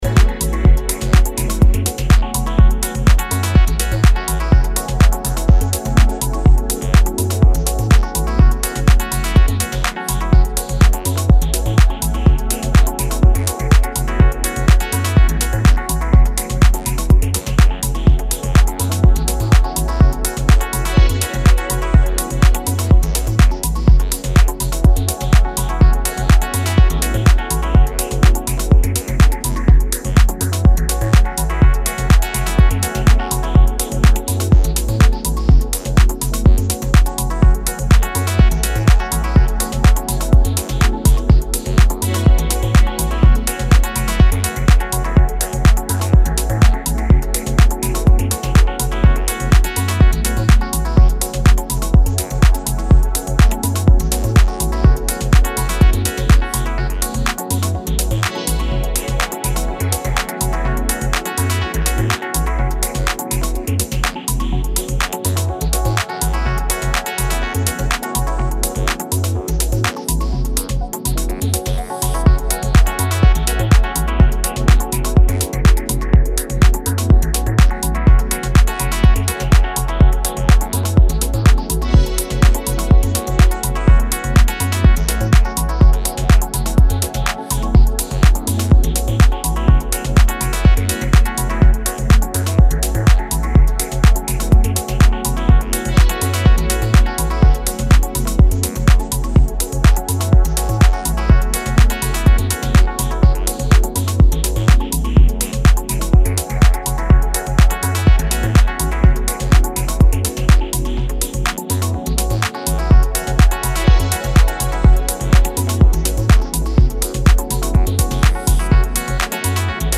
3 original tracks crafted for peak moments on the dancefloor